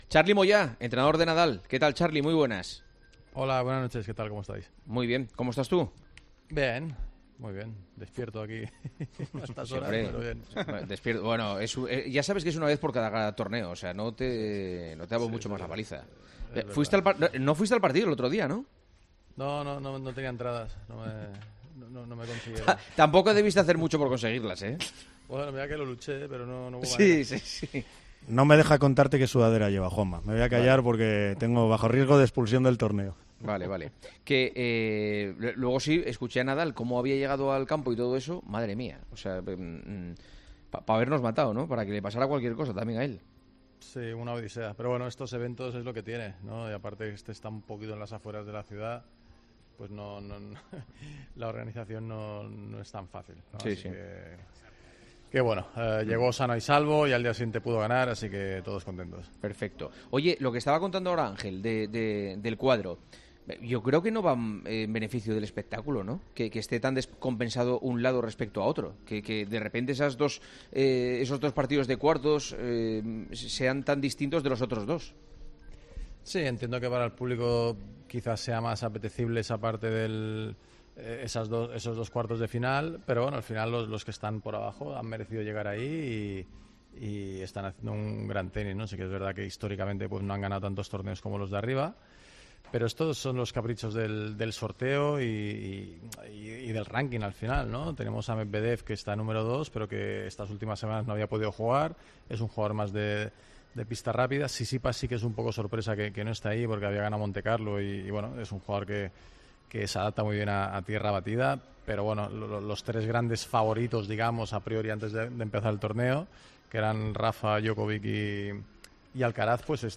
ENTREVISTA A CARLOS MOYÁ, EN EL PARTIDAZO DE COPE